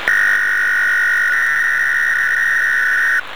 Фрагмент сигнала 2812 кГц
2812khz_fragm.wav